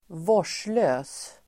Uttal: [²v'år_s:lö:s]